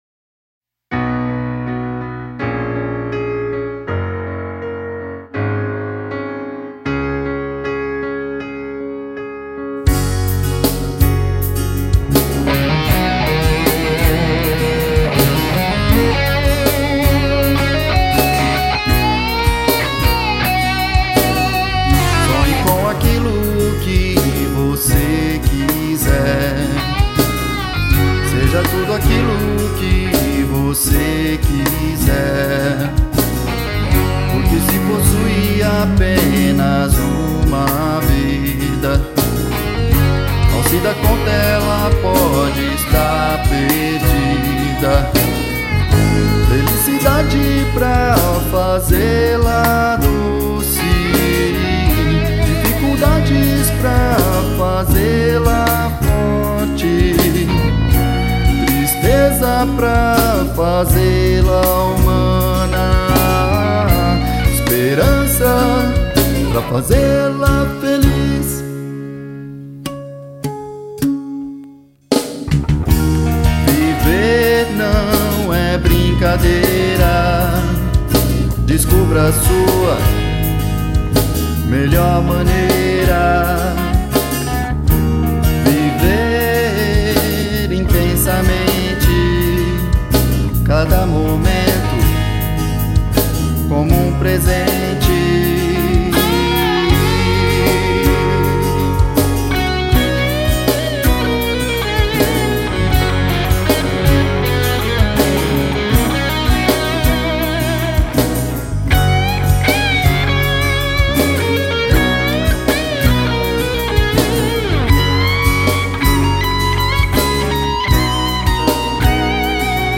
EstiloJazz